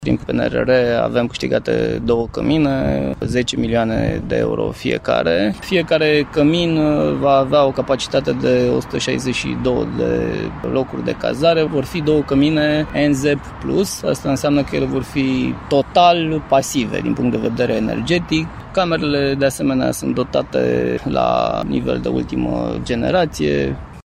16 dintre străzi se află în lucrări de asfaltare, spune primarul comunei Dumbrăvița Horia Bugarin.